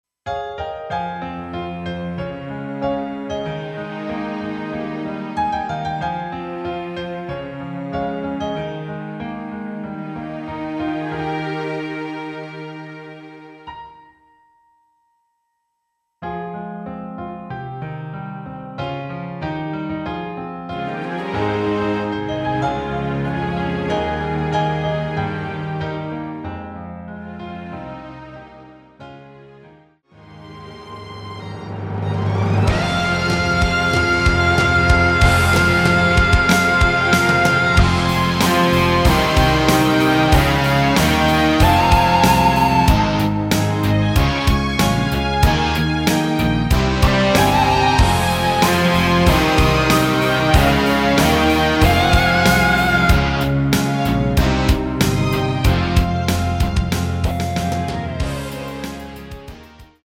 Rock 축가!!
Fm
앞부분30초, 뒷부분30초씩 편집해서 올려 드리고 있습니다.
곡명 옆 (-1)은 반음 내림, (+1)은 반음 올림 입니다.